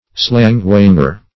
Search Result for " slang-whanger" : The Collaborative International Dictionary of English v.0.48: Slang-whanger \Slang"-whang`er\, n. [Slang + whang to beat.] One who uses abusive slang; a ranting partisan.
slang-whanger.mp3